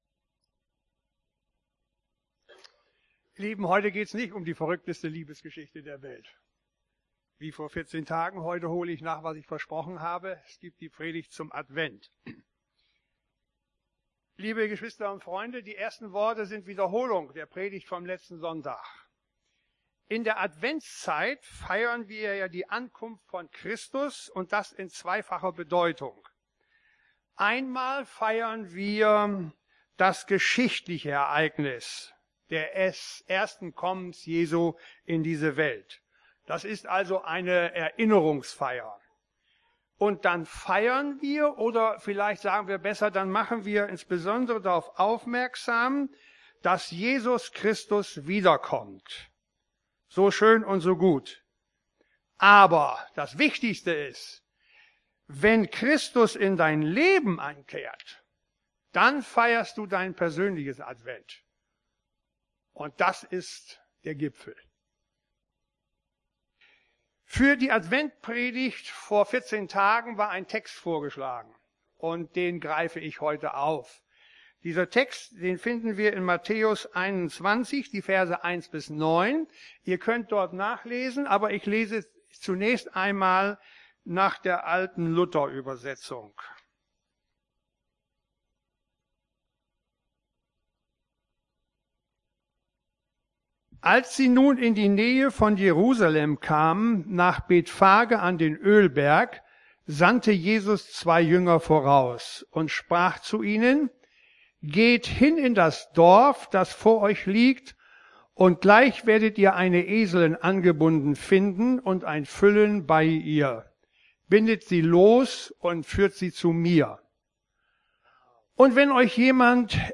Predigt vom 14.12.2014